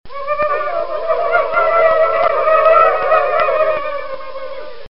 Толпа индейцев издает звуки ртом перед атакой или заметив чужака